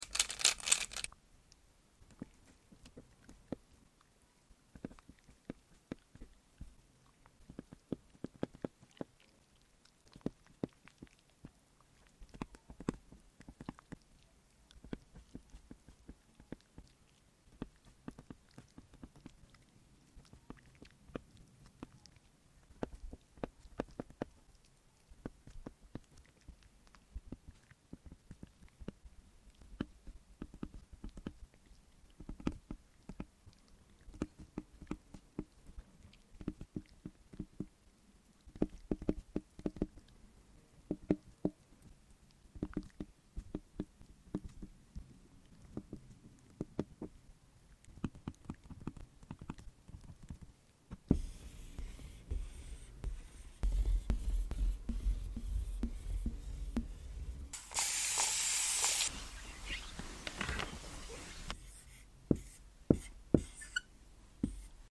ASMR Will it whiteboard? Silky sound effects free download
Silky Mp3 Sound Effect ASMR Will it whiteboard? Silky crayons! ✨🖍 They were so satisfying to write with!